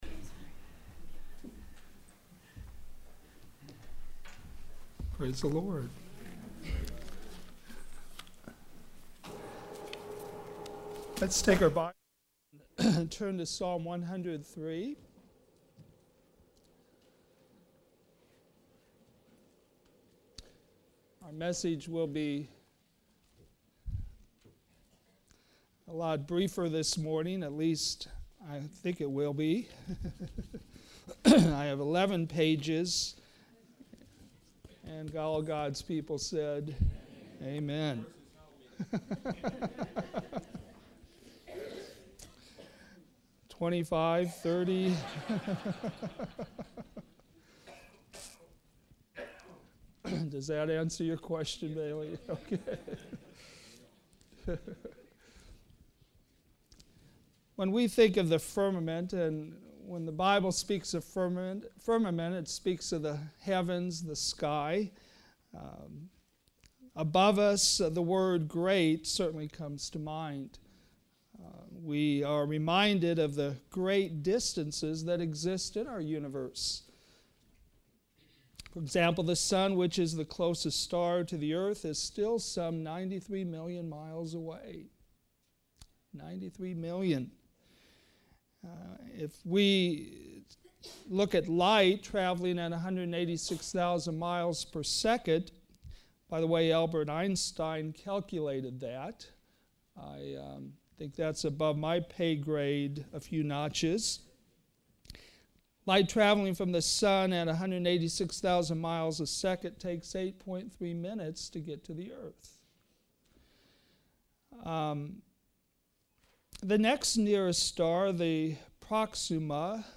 All Sermons - Westside Baptist Church